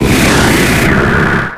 72c574598dd1f7f90bcadb025ec7920aad8589a3 infinitefusion-e18 / Audio / SE / Cries / ALAKAZAM.ogg infinitefusion d3662c3f10 update to latest 6.0 release 2023-11-12 21:45:07 -05:00 16 KiB Raw History
ALAKAZAM.ogg